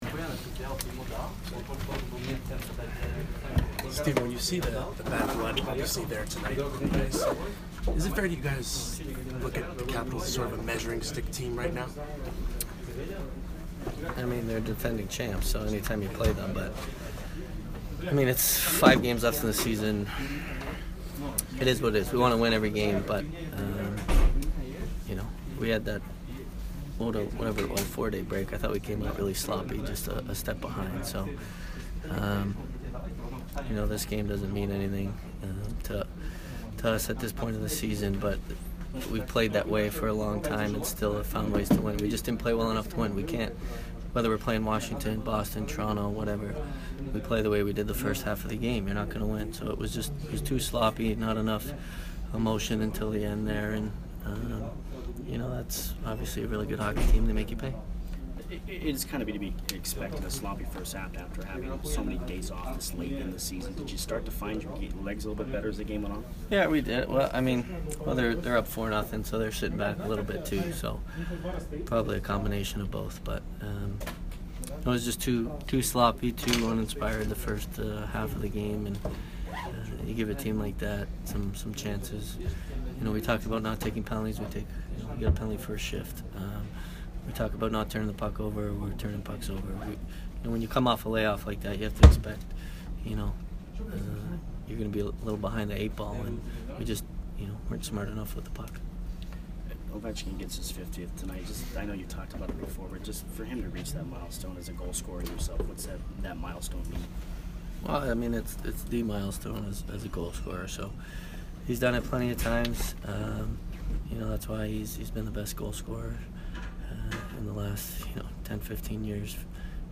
Steven Stamkos post-game 3/30